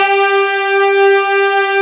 Index of /90_sSampleCDs/Roland LCDP06 Brass Sections/BRS_Fat Section/BRS_Fat Pop Sect